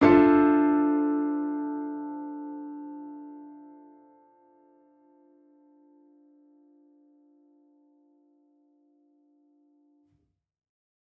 Index of /musicradar/gangster-sting-samples/Chord Hits/Piano
GS_PiChrd-Dmin6+9.wav